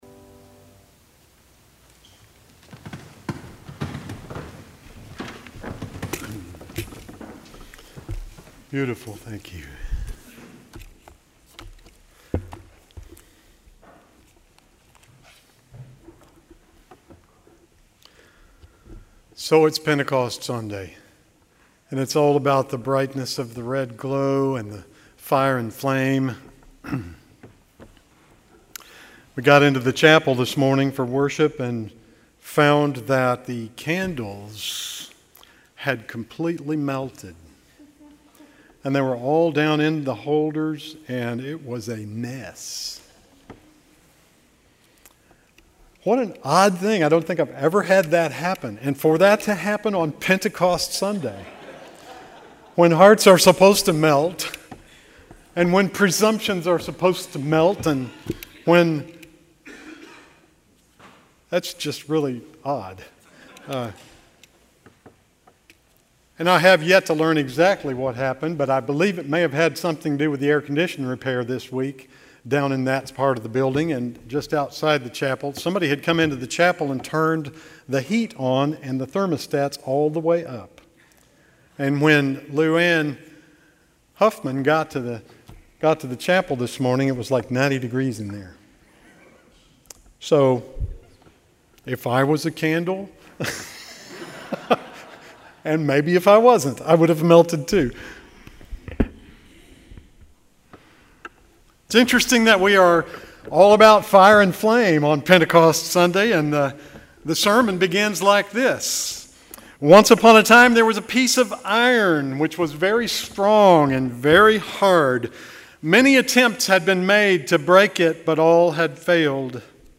June 9, 2019 Sermon